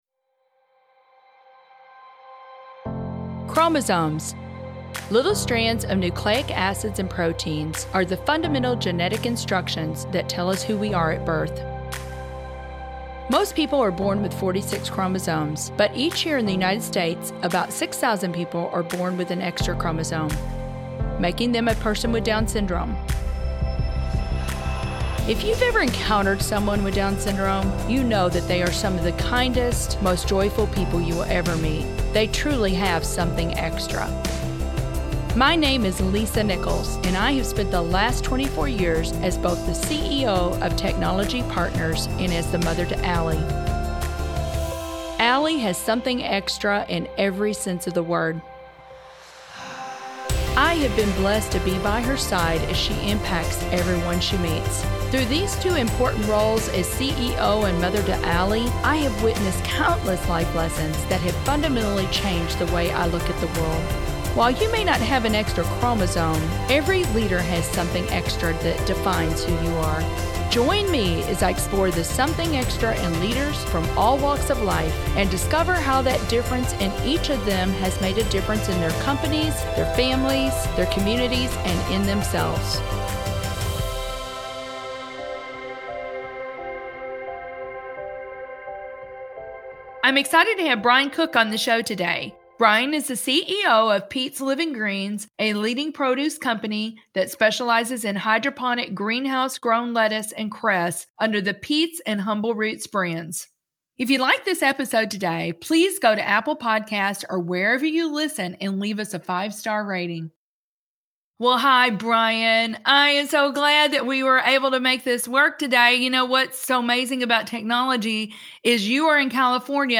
Lisa Nichols, Host